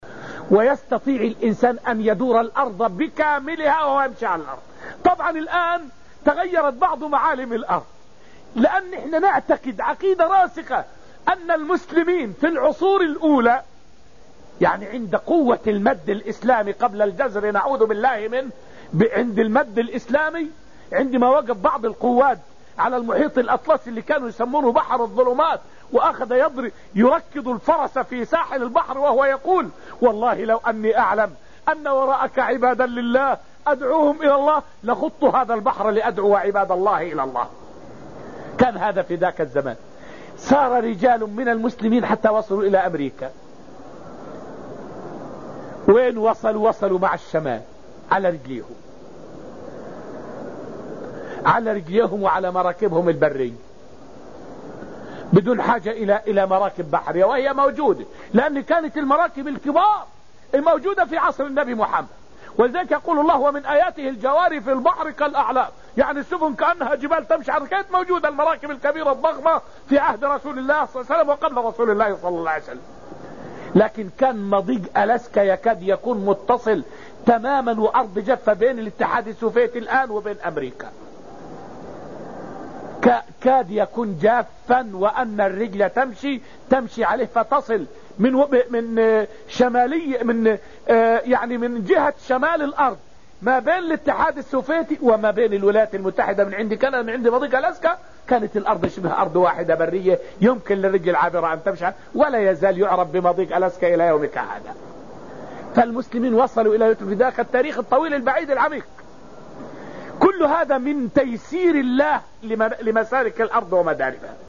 فائدة من الدرس السادس من دروس تفسير سورة الذاريات والتي ألقيت في المسجد النبوي الشريف حول بيان كيف وصل الفتح الإسلامي لأقصى الأرض؟